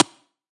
来自我的卧室的声音" 录音棚2 ( 冻结 )
描述：在Ableton中录制并略微修改的声音